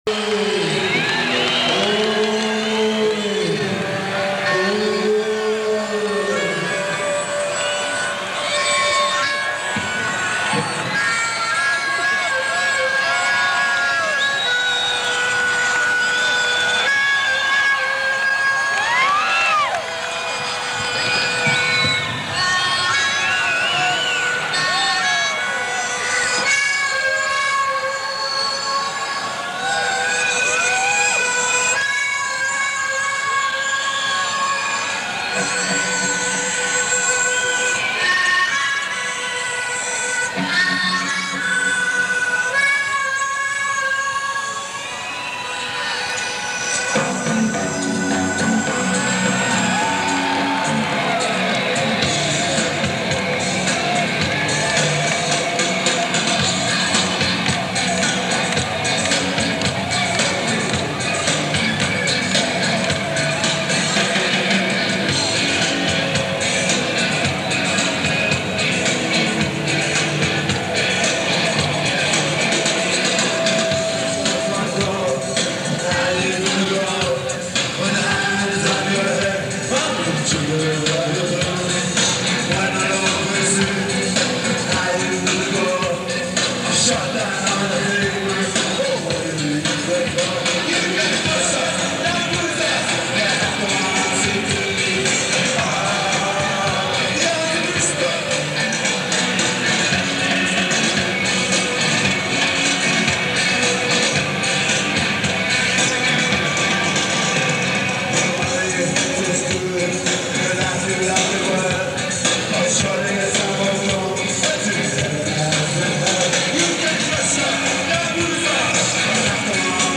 Spectrum, Philadelphia 4-22-84